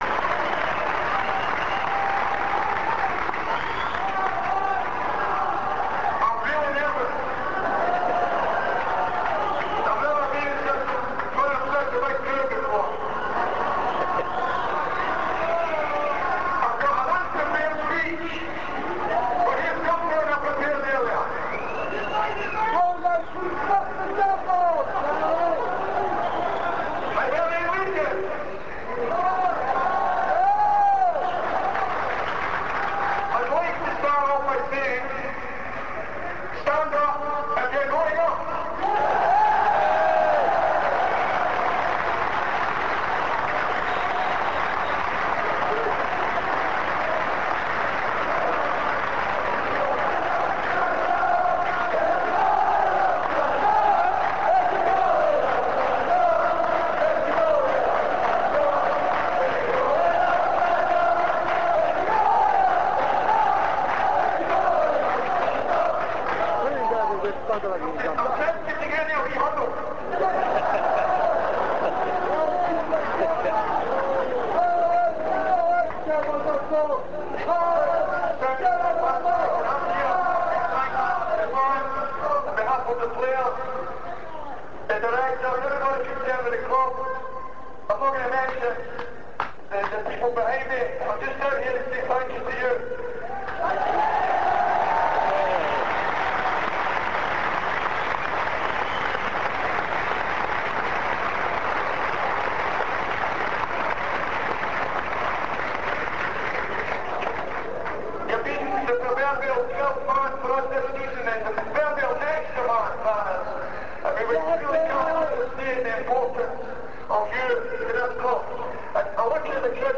This page contains, for the moment, chants from the last game of the season at Easter Road on 8th May 1999, along with Alex McLeish's speech after the game.
alex_mcleish_speech.rm